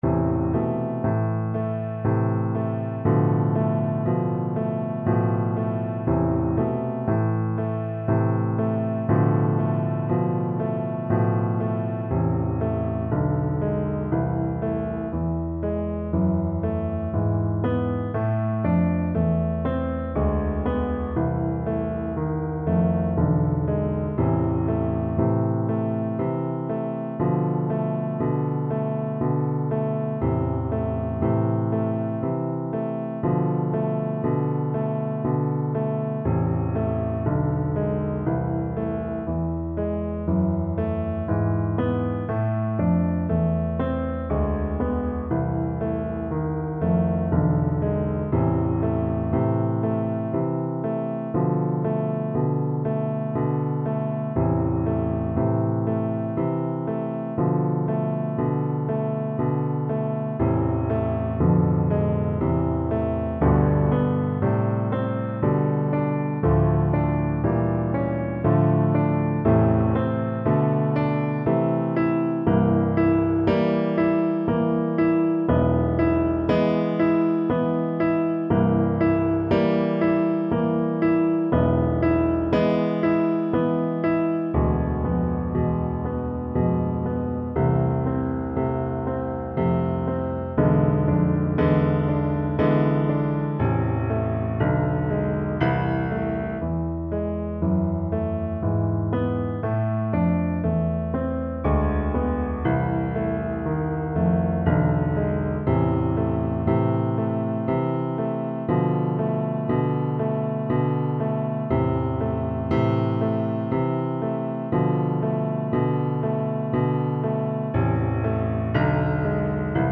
3/4 (View more 3/4 Music)
Classical (View more Classical Bassoon Music)